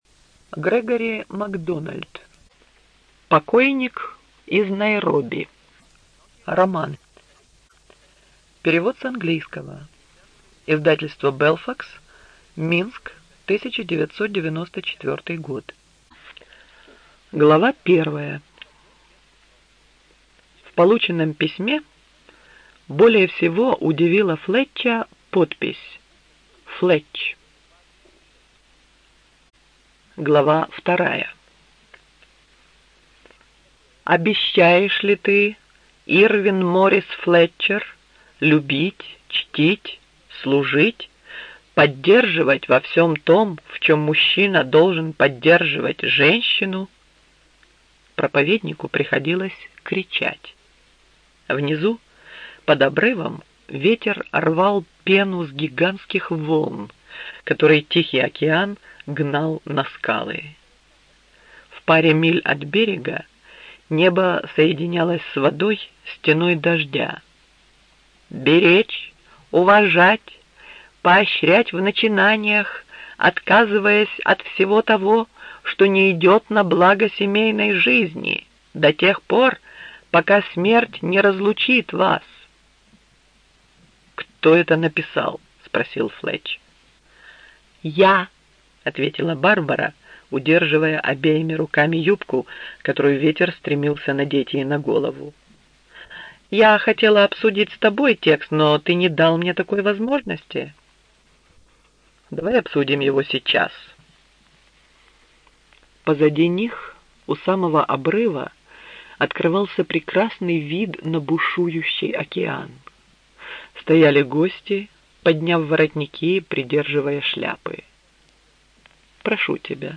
Студия звукозаписиРеспубликанский дом звукозаписи и печати УТОС
В аудиокниге можно услышать роман известного американского писателя Г. Макдональда о захватывающих приключениях журналиста Флетча «Покойник из Найроби».